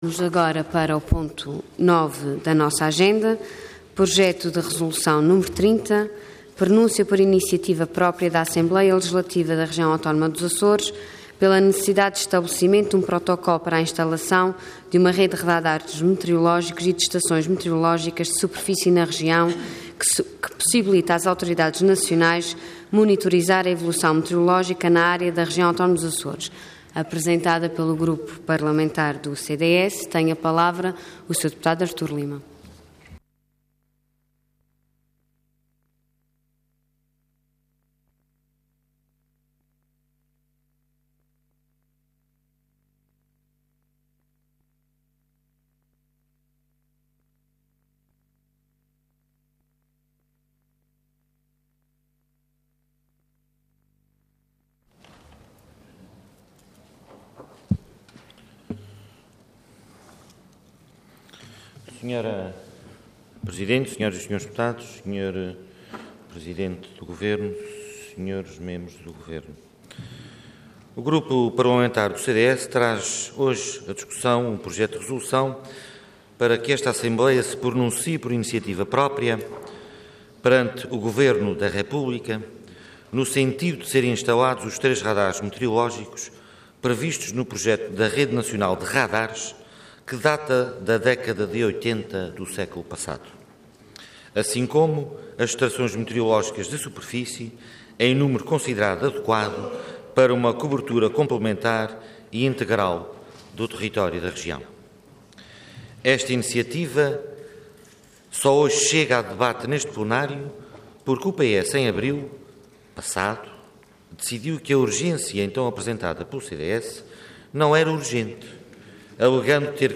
Intervenção Projeto de Resolução Orador Artur Lima Cargo Deputado Entidade CDS-PP